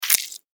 戦闘 （163件）
クリーチャーが食べる音2.mp3